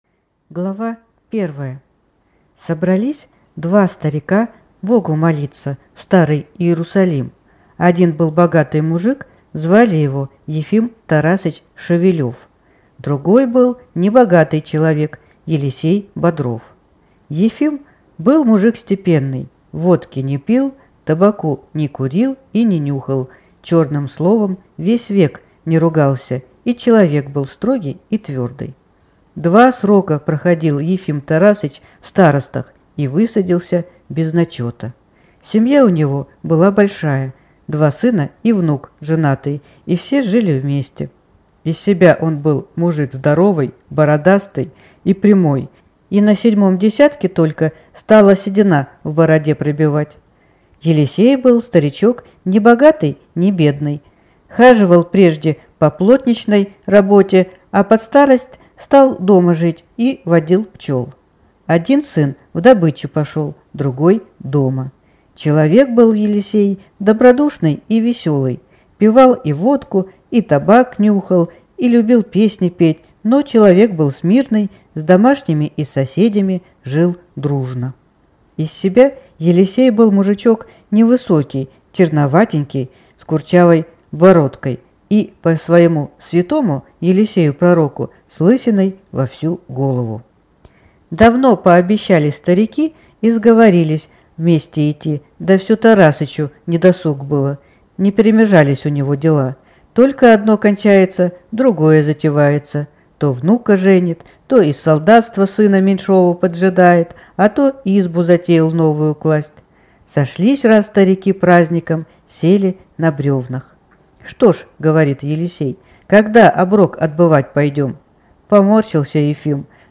Аудиокнига Два старика